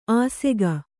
♪ āsega